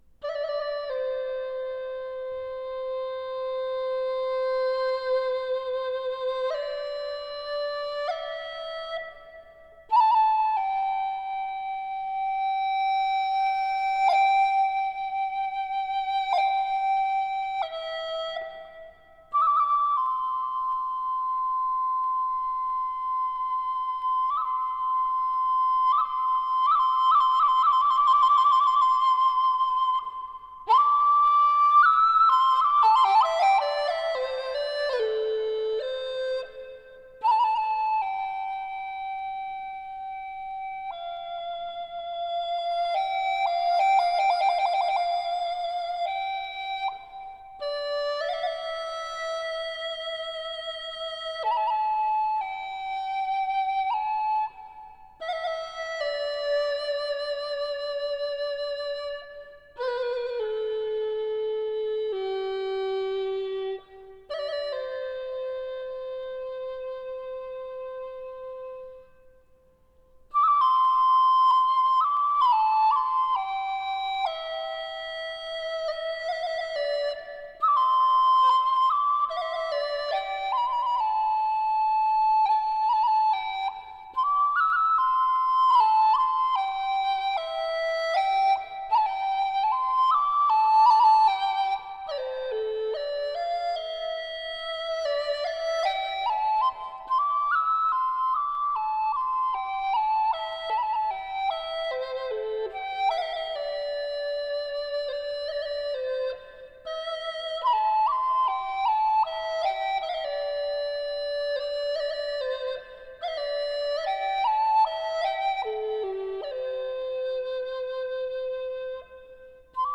Китайская музыка Медитативная музыка Флейта